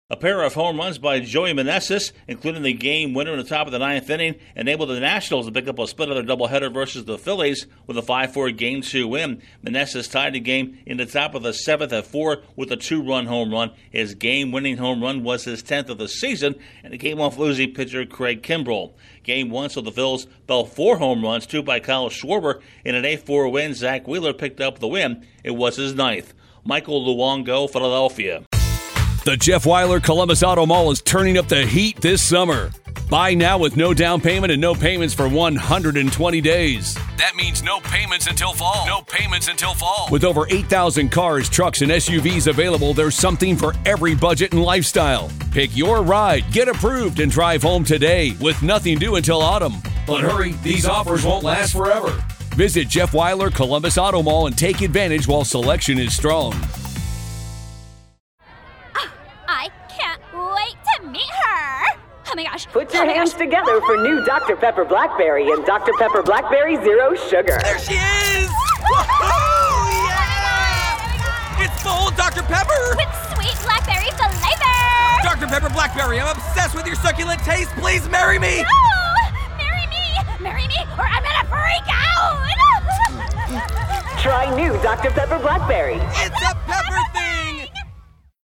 The Nationals earn a split of their twinbill with the Phillies. Correspondent